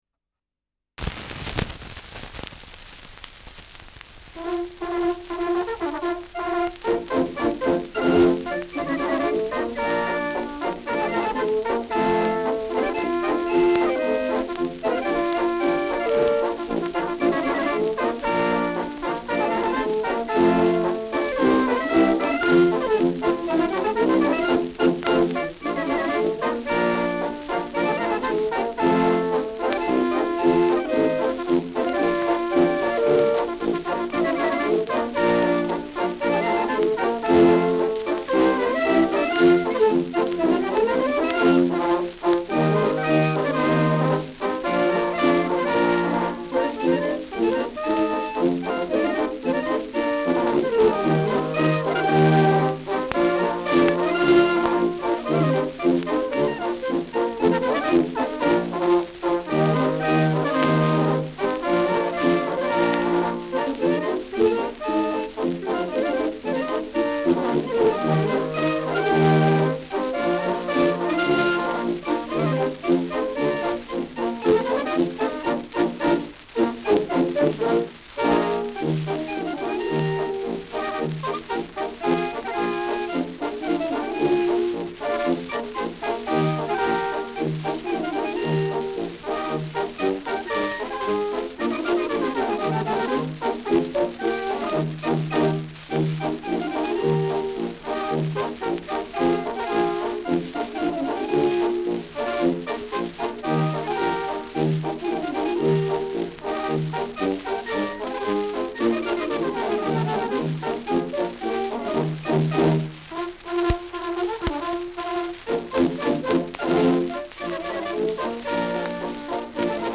Schellackplatte
Stärkeres Grundrauschen : Gelegentlich leichtes bis stärkeres Knacken
Kapelle Wittelsbach, Regensburg (Interpretation)
[Nürnberg] (Aufnahmeort)